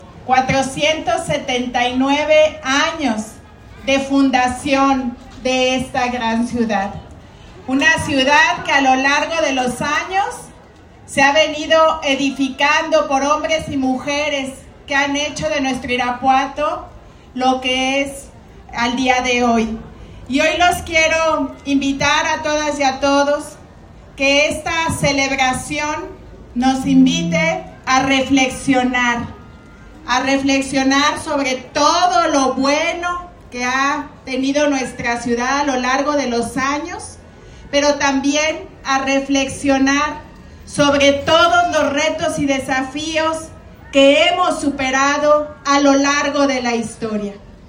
AudioBoletines
Lorena Alfaro García, presidenta de Irapuato